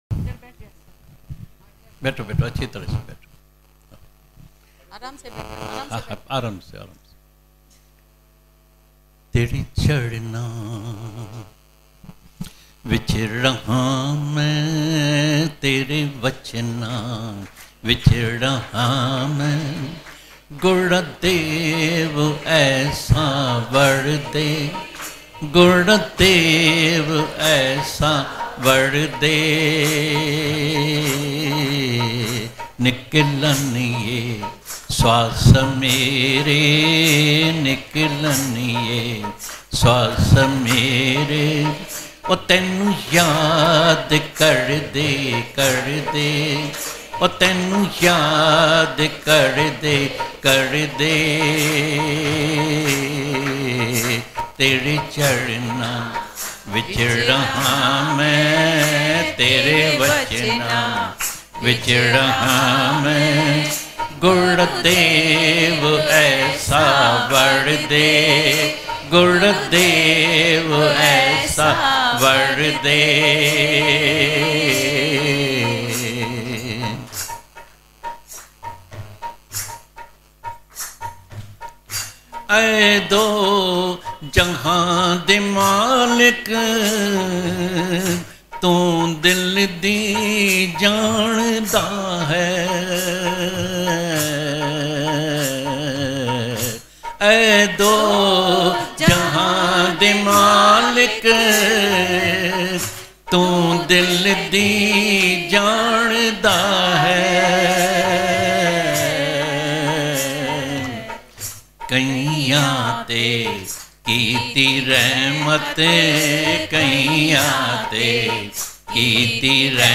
Tere Charna Vich Raha Mein Tere Vachna Bhajan | तेरे चरणा विच रहां मैं तेरे वचनां विच रहां मैं भजनDivine Shyam Bhagwan Punjabi Bhajans
Tere-Charna-Vich-Raha-Mein-Tere-Vachna-Bhajan.mp3